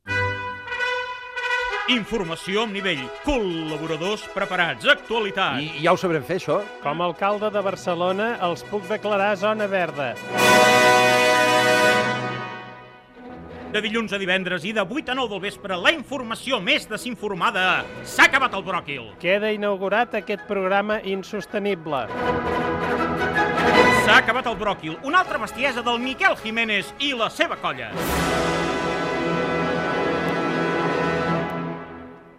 8a8f0a19dedc448ca899a212c3dd29a1a1bda60c.mp3 Títol COM Ràdio Emissora COM Ràdio Barcelona Cadena COM Ràdio Titularitat Pública nacional Nom programa S'ha acabat el bròquil Descripció Promoció del programa.